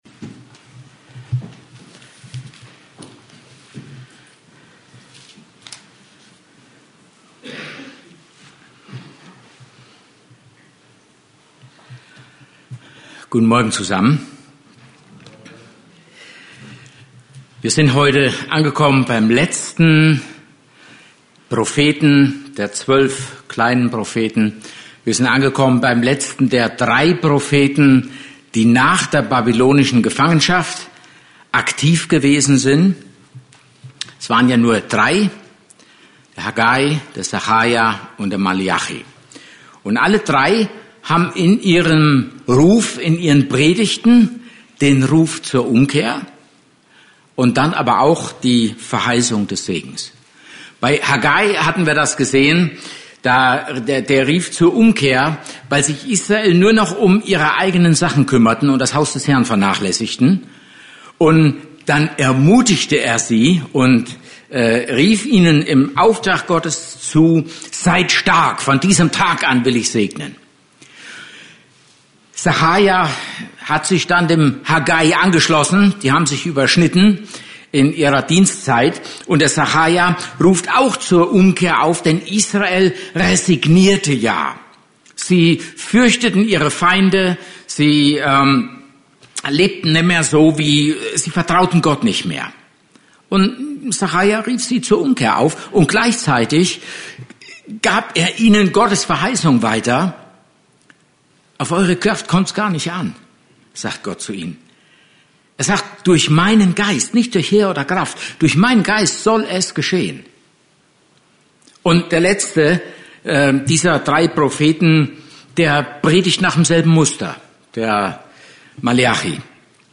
Service Type: Gottesdienst